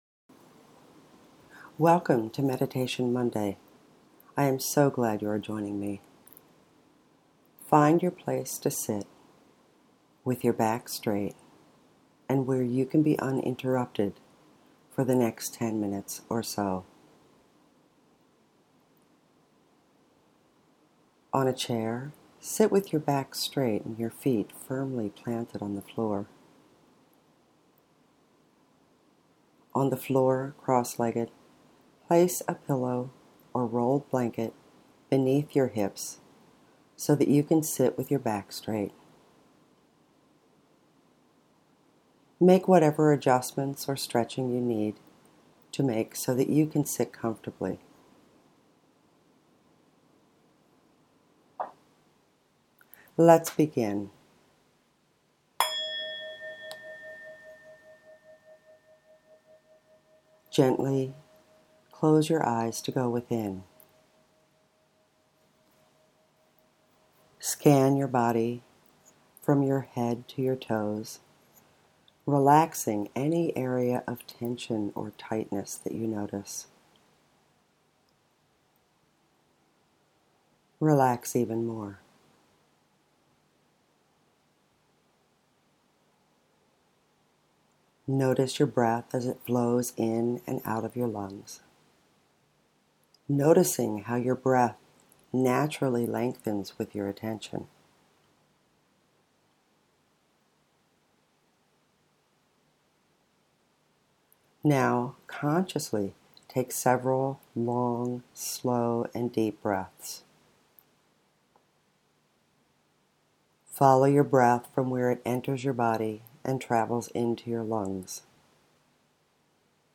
meditation-17.mp3